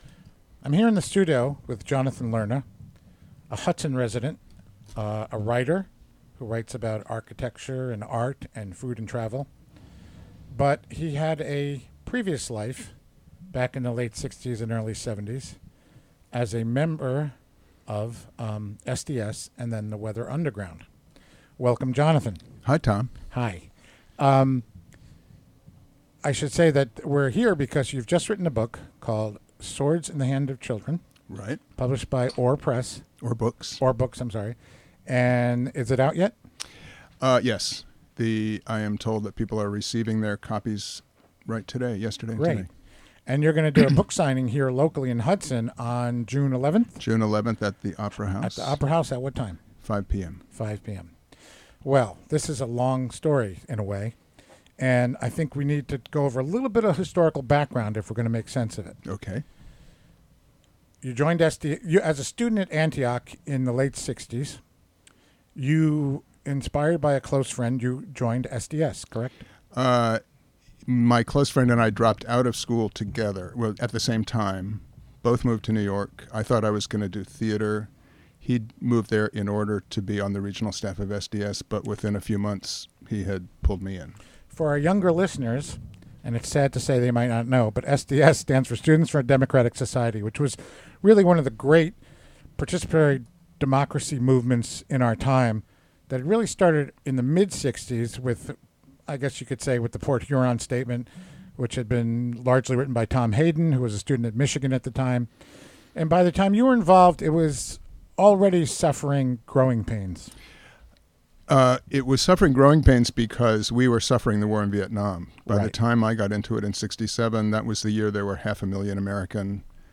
Recorded Wed., May 31, 2017, in the WGXC Hudson Studio.